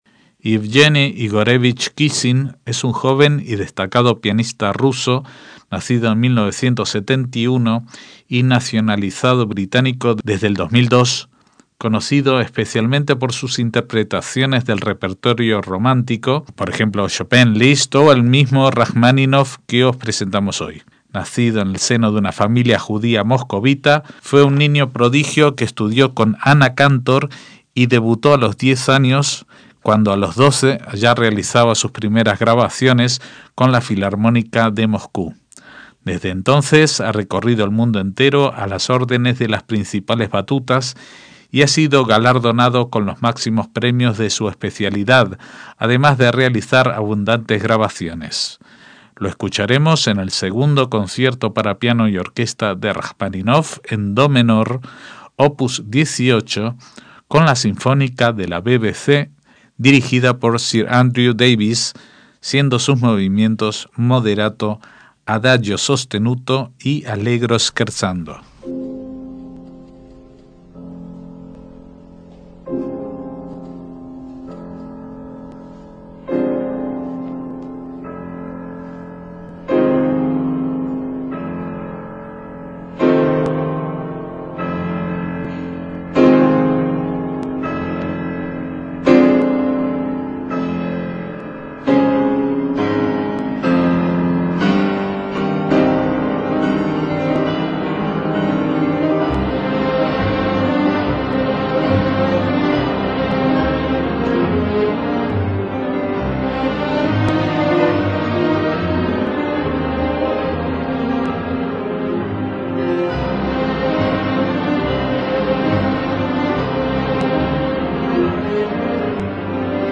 MÚSICA CLÁSICA
Moderato, Adagio sostenuto y Allegro scherzando